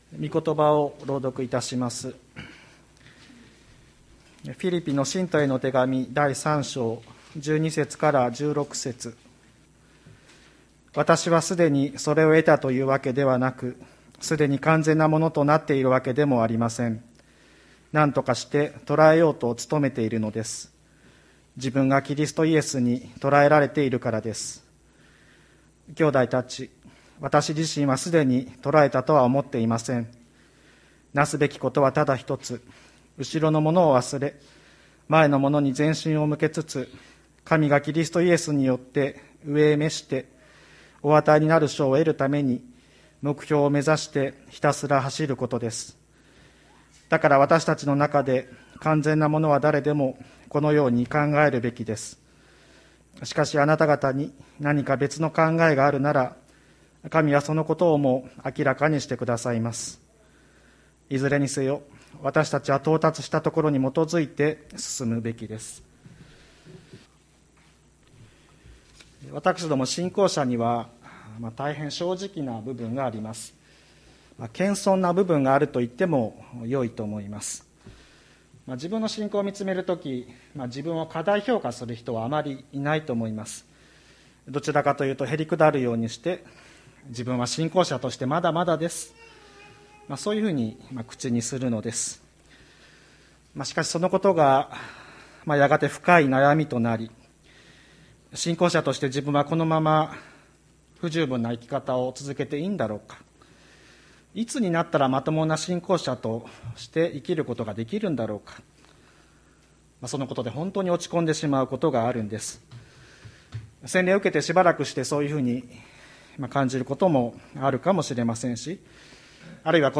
2021年01月31日朝の礼拝「目標を目指してひたすら走る」吹田市千里山のキリスト教会
千里山教会 2021年01月31日の礼拝メッセージ。